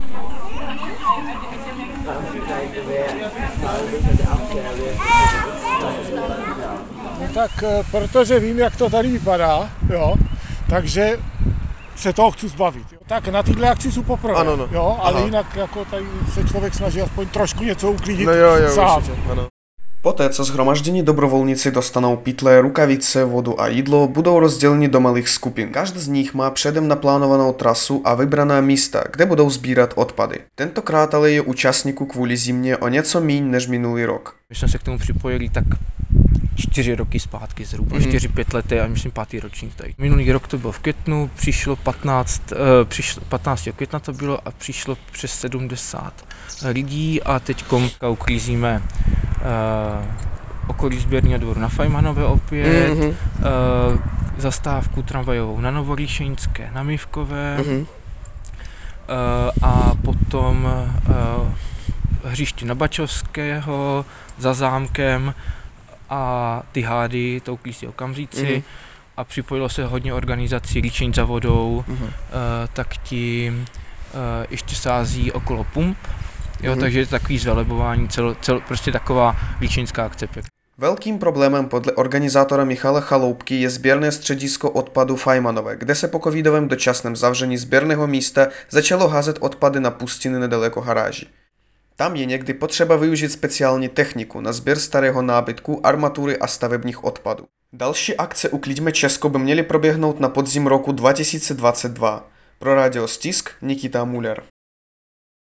Podle organizátorů je letošní akce Ukliďme Česko rekordní v počtu nahlášených míst, kterých je více než 3300. V některých regionech republiky se k dobrovolnictví připojili příslušníci vietnamské a ukrajinské komunity. Důvody, proč se k akci připojil, vysvětluje jeden z dobrovolníků.